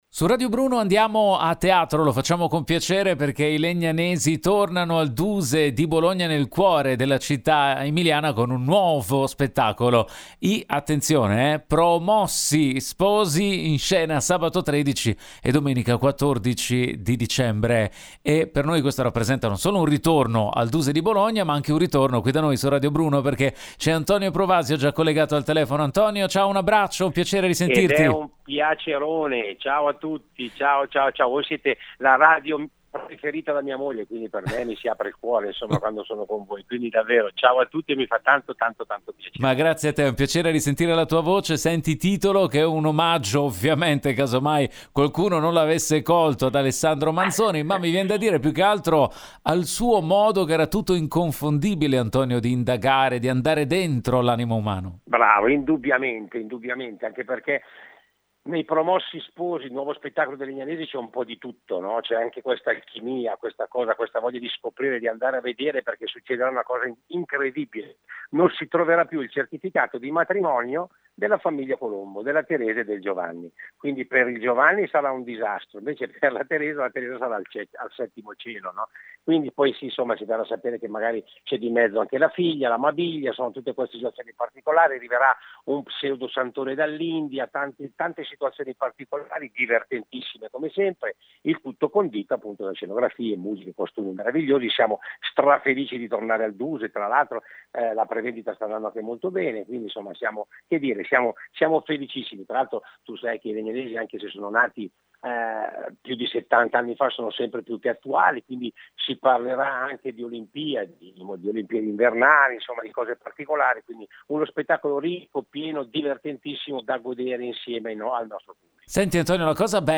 Home Magazine Interviste I Legnanesi tornano al Duse di Bologna con “I Promossi Sposi”